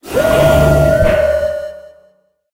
Cri de Zamazenta dans sa forme Héros Aguerri dans Pokémon HOME.
Cri_0889_Héros_Aguerri_HOME.ogg